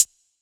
Closed Hats
YK - Metro HH (Hats)(1).wav